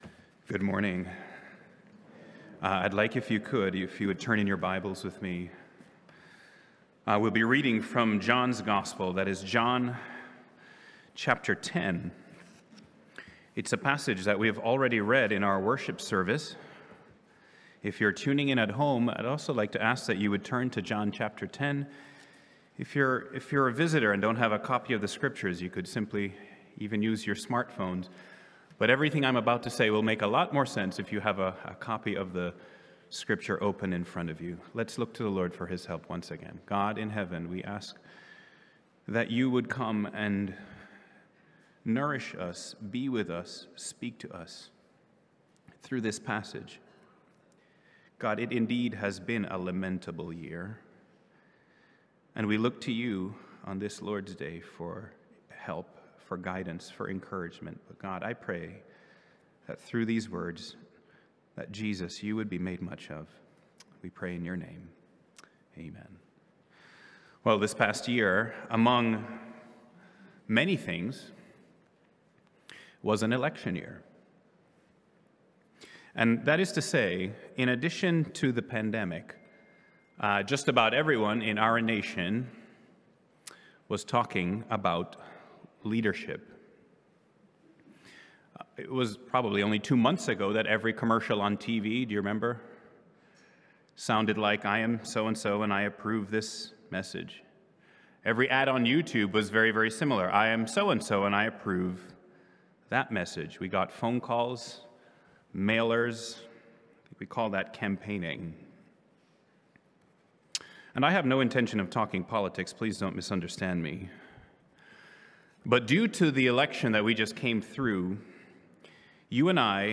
Sermons on John 10:11-21 — Audio Sermons — Brick Lane Community Church